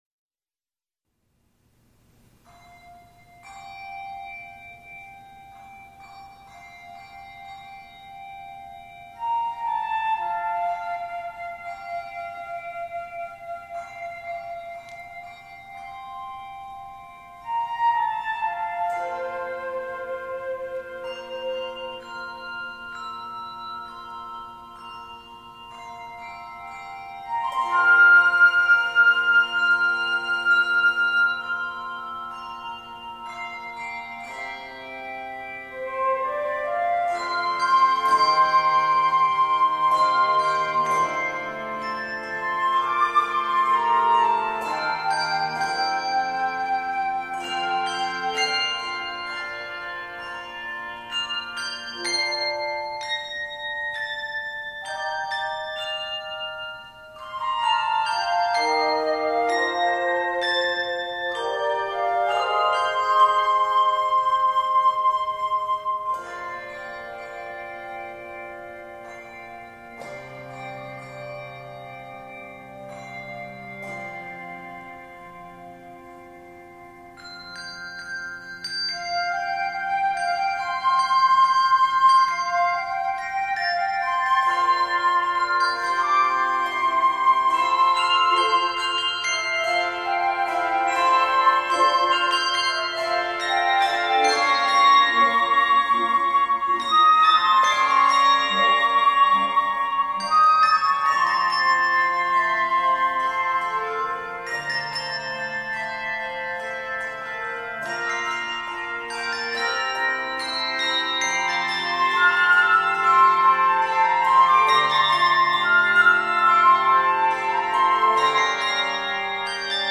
it is scored in F Major.